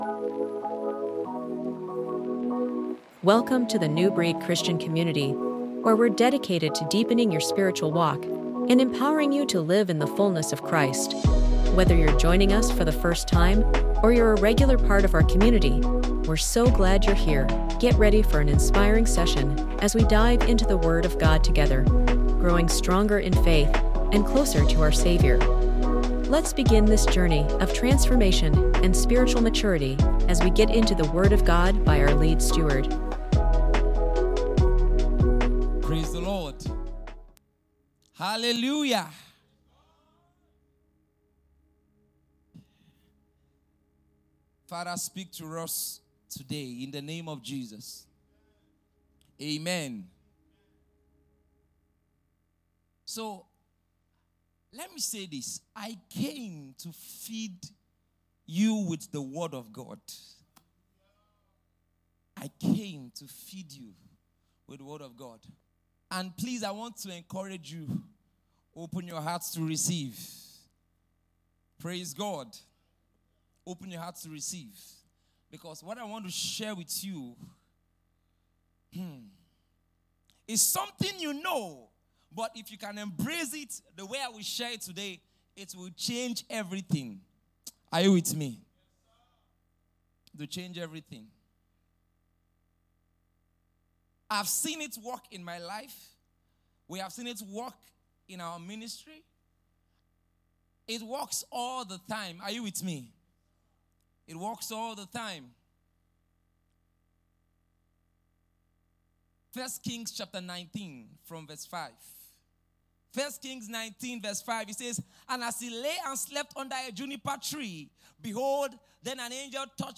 We’re excited to bring you a powerful teaching from our special program held in the city of Port Harcourt, titled: 📖 “For I Long To See You” In this soul-stirring message